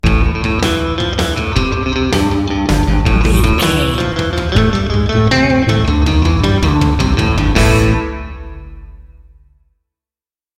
Aeolian/Minor
groovy
driving
energetic
bass guitar
drums
electric guitar